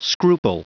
Prononciation du mot scruple en anglais (fichier audio)
Prononciation du mot : scruple